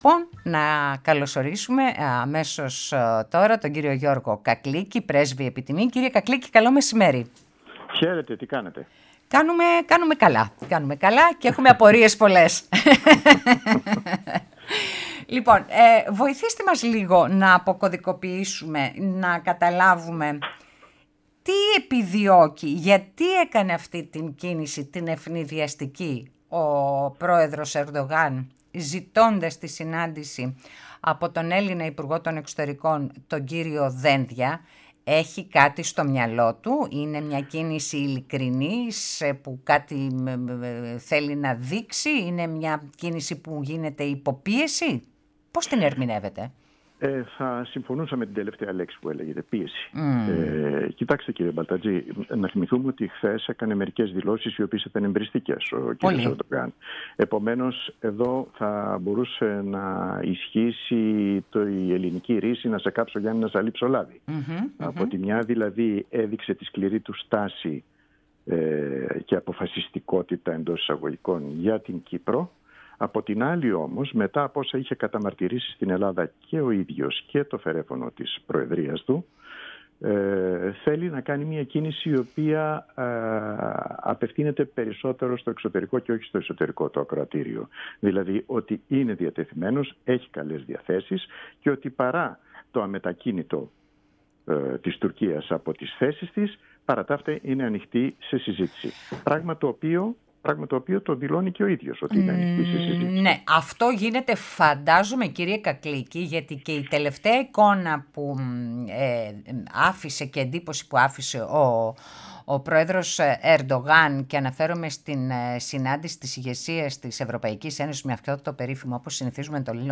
μιλώντας στο Πρώτο Πρόγραμμα